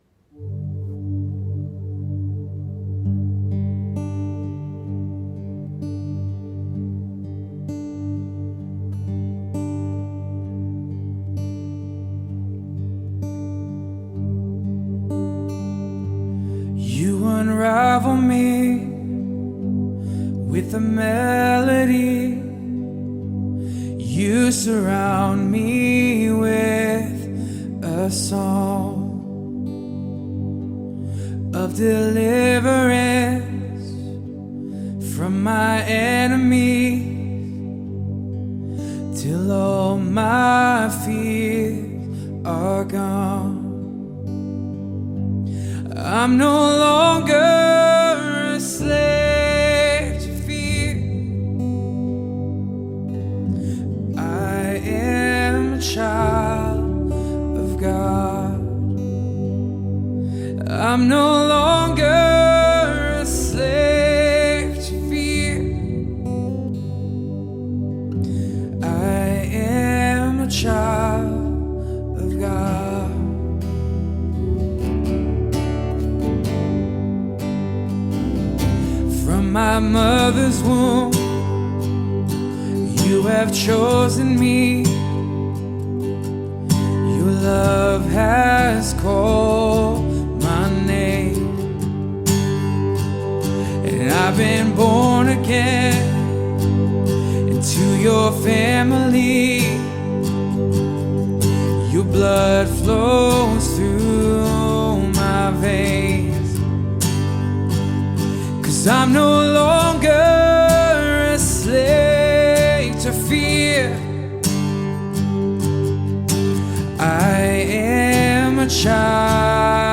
3. Sunday Worship – Second Song: